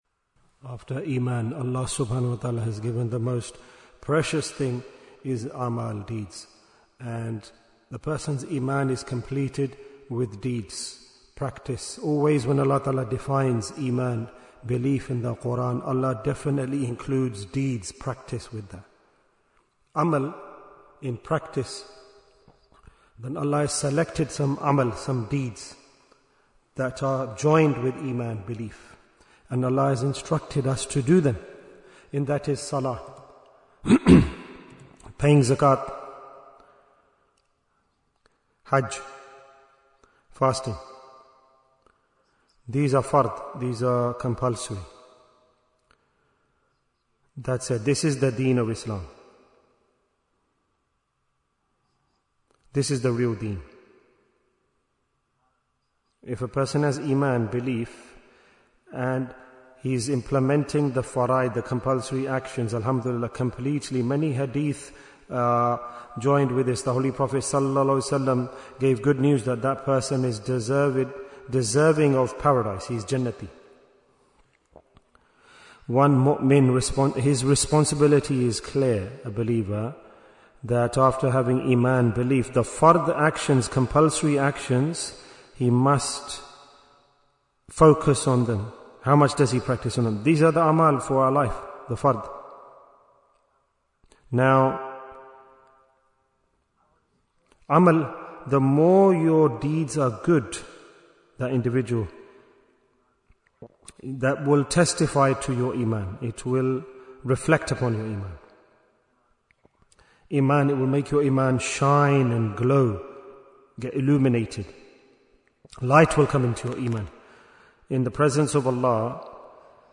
Why is Tazkiyyah Important? - Part 1 Bayan, 88 minutes10th January, 2026